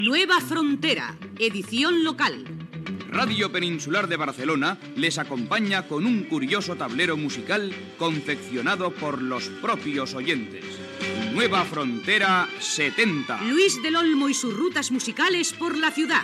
Promoció del programa
Programa presentat per Luis del Olmo.